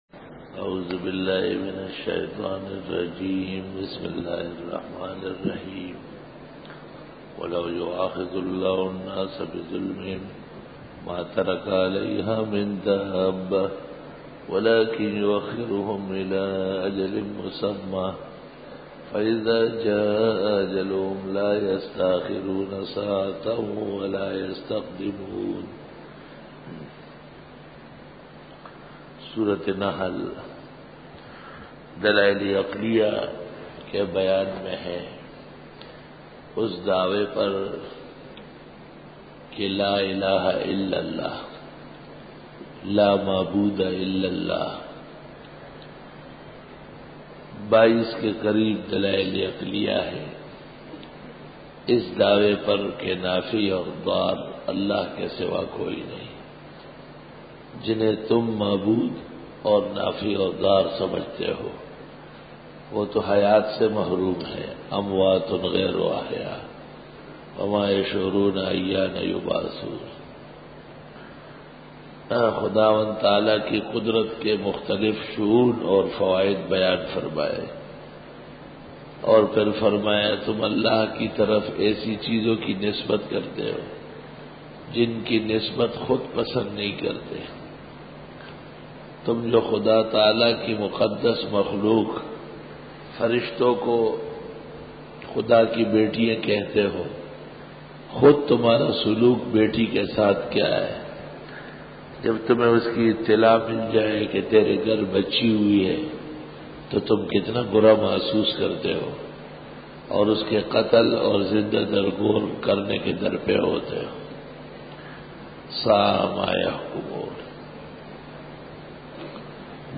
سورۃ النحل رکوع-08 Bayan